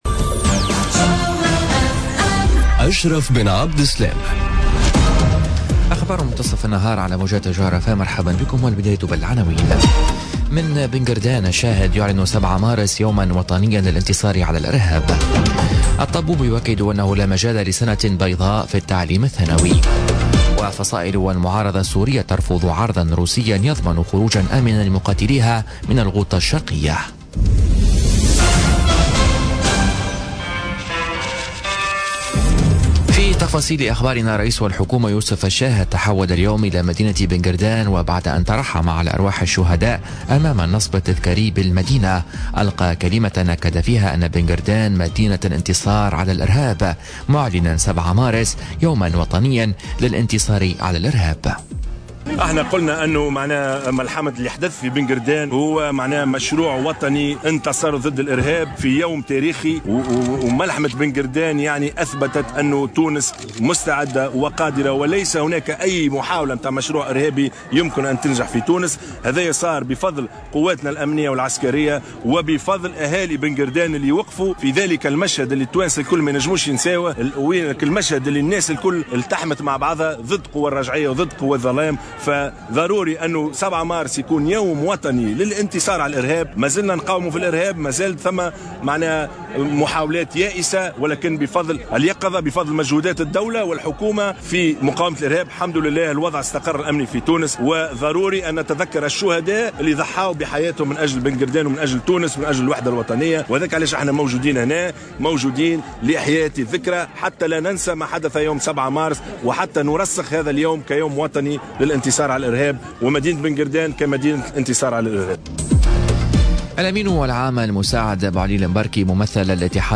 نشرة أخبار منتصف النهار ليوم الإربعاء 7 مارس 2018